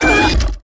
naga_commander_damage.wav